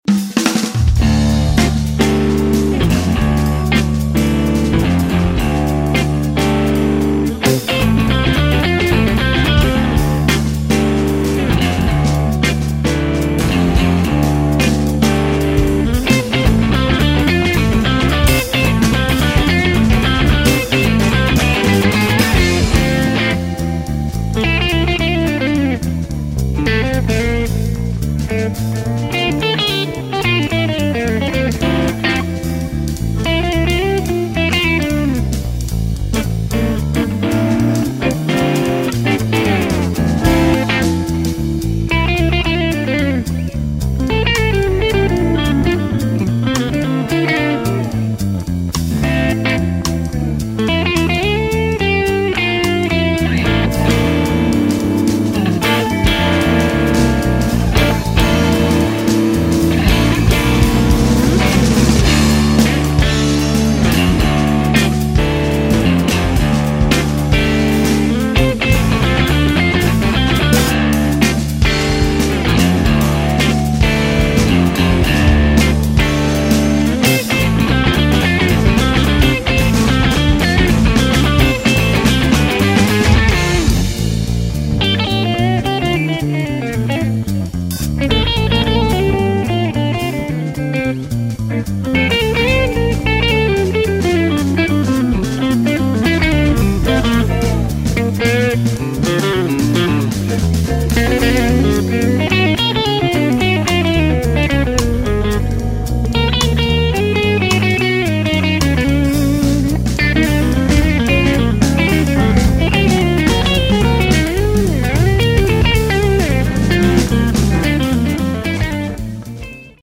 a blend of rock fusion and progressive jazz
Bass
Drums
Instrumental Rock